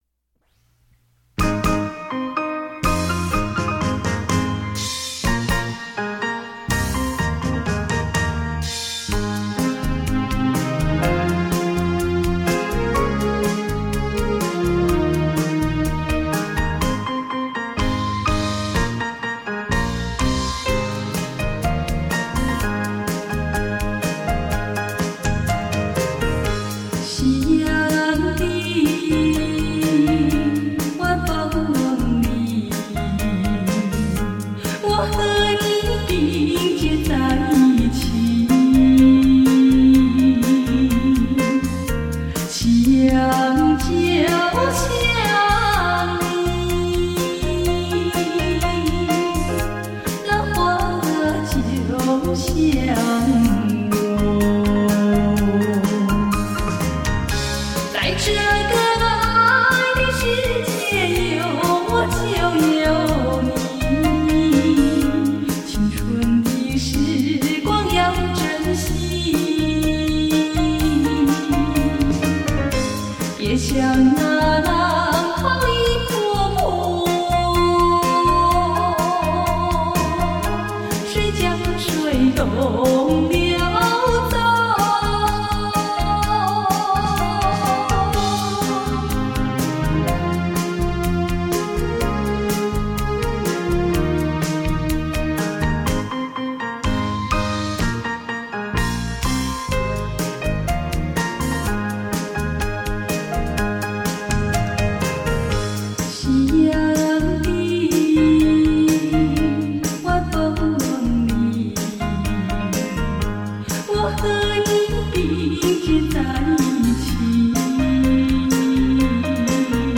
最经典的华丽风格 最熟悉的国语老歌